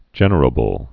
(jĕnər-ə-bəl)